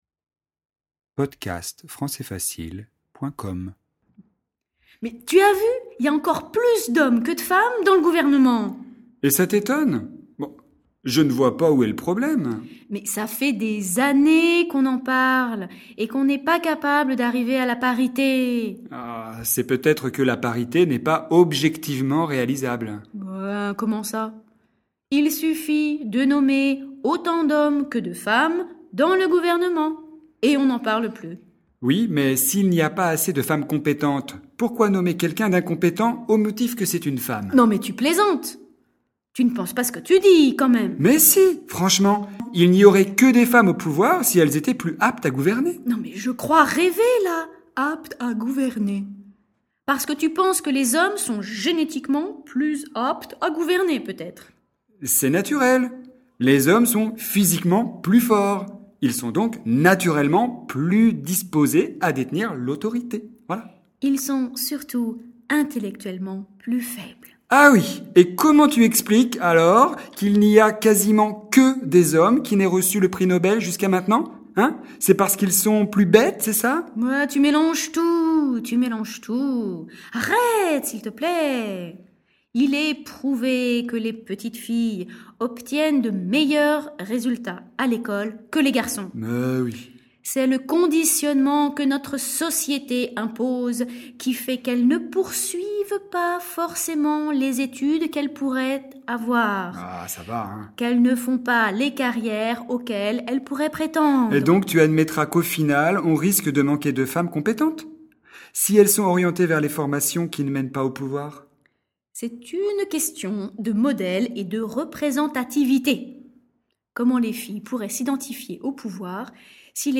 Misogyne - Dialogue FLE